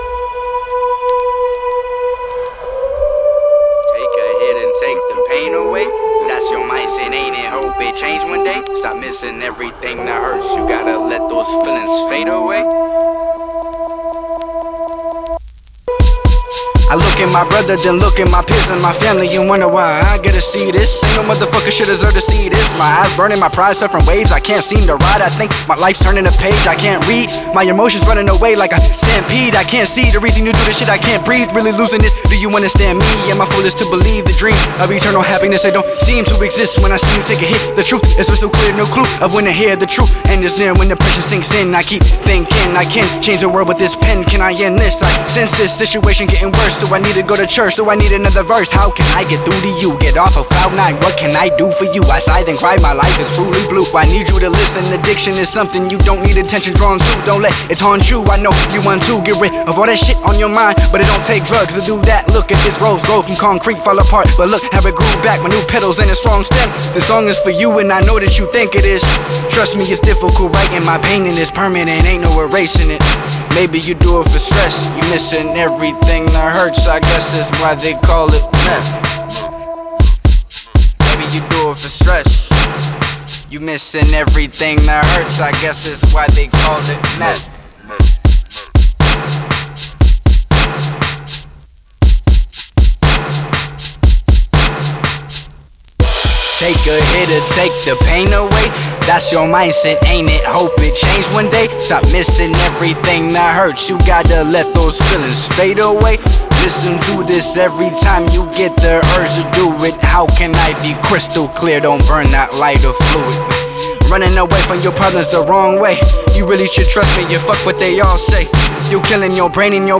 With a tongue-twisting cadence